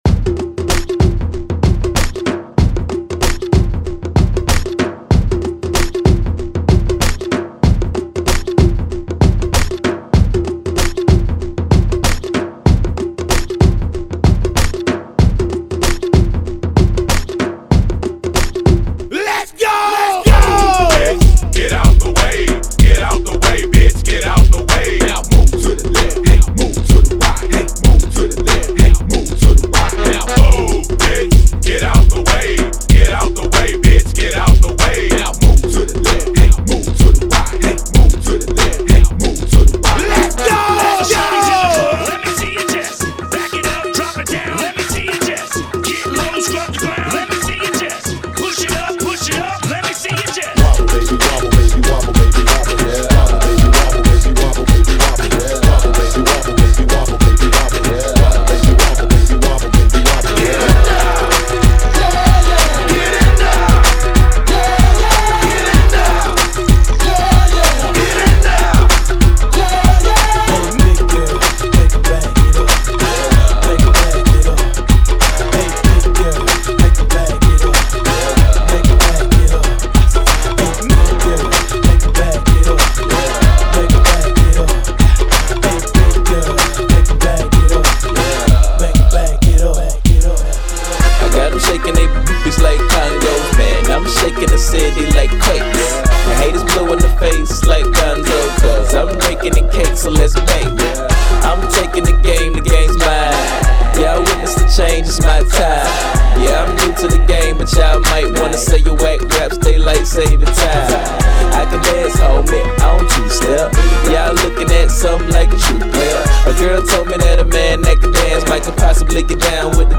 95 BPM ] [Indian Style